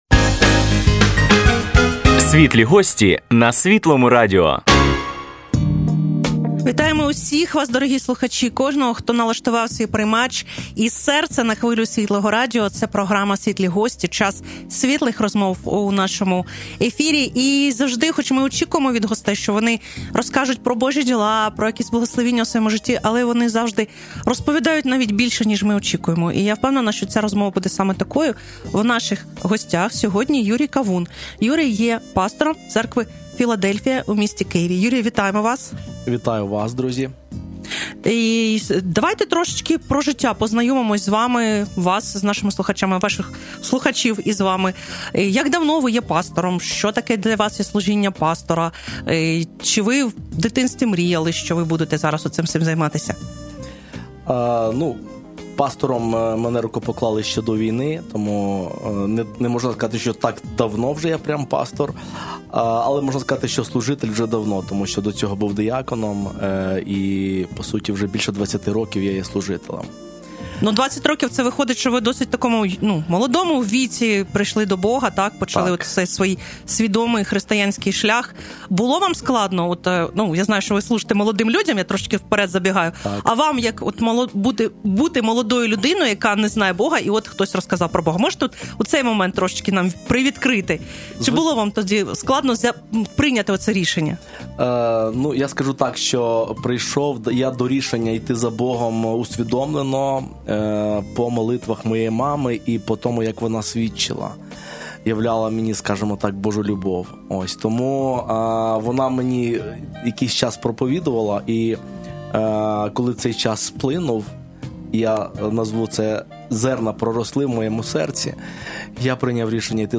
Тож, поговорили про закоханість та завищені очікування, конфлікти, побачення, знайомство з батьками та свободу вибору. Практична й глибока бесіда, що допоможе збудувати щасливе...